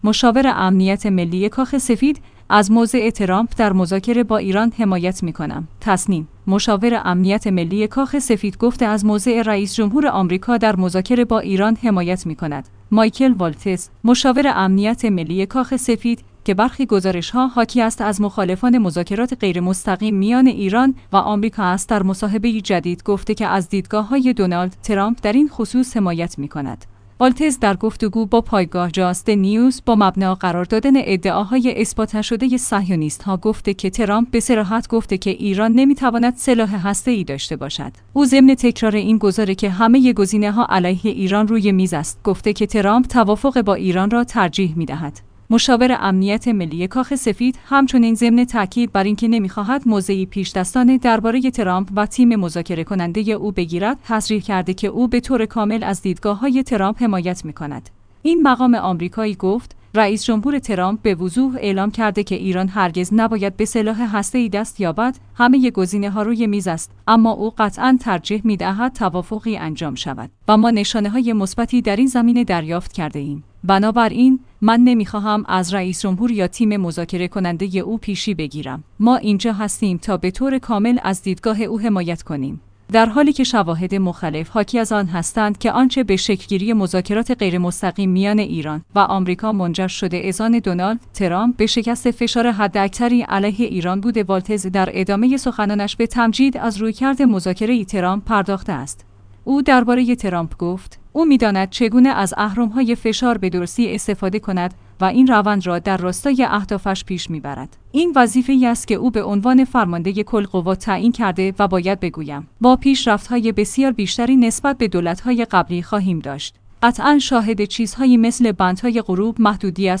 مایکل والتز، مشاور امنیت ملی کاخ سفید که برخی گزارش‌ها حاکی است از مخالفان مذاکرات غیرمستقیم میان ایران و آمریکا است در مصاحبه‌ای جدید گفته که از دیدگاه‌های دونالد ترام‍پ در این خصوص حمایت می‌کند.